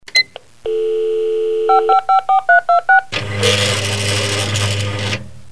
传真机音效